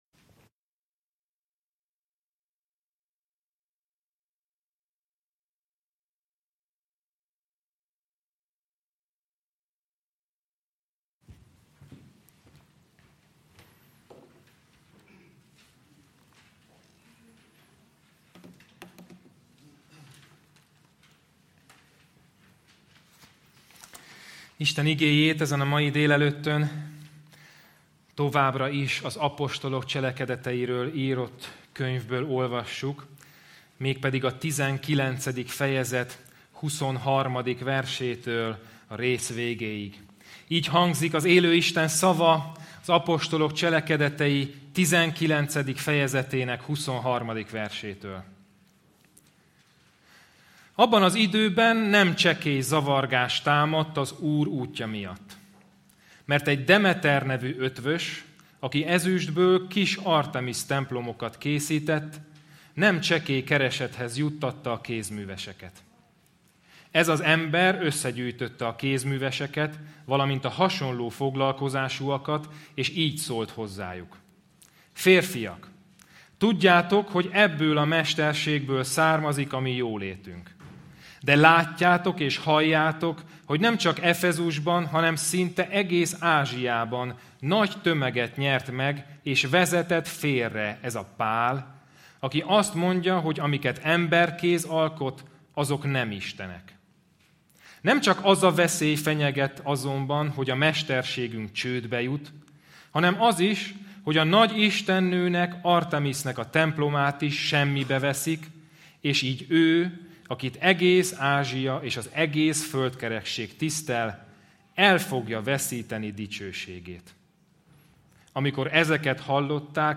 Service Type: Igehirdetés